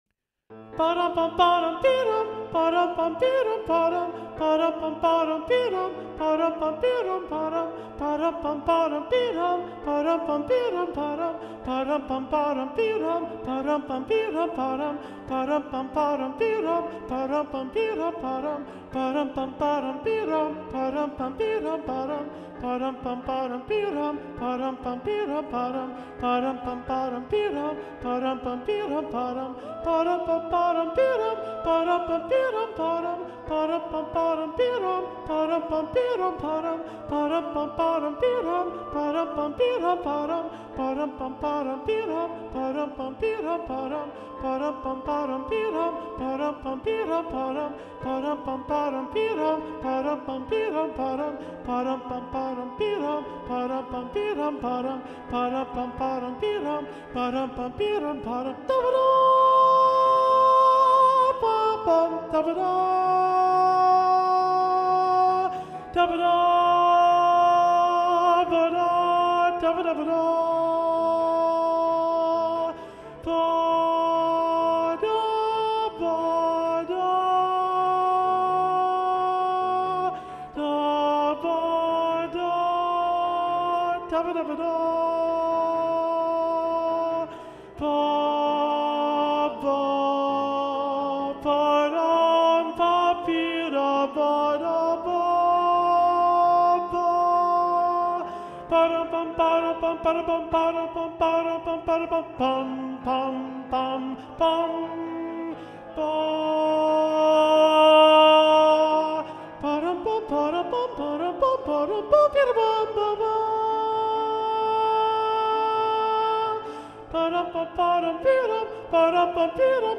- Œuvre pour chœur à 7 voix mixtes (SSAATTB) + piano
SATB Alto 2 (chanté)